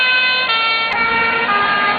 feuerwehr_ausschnitt.wav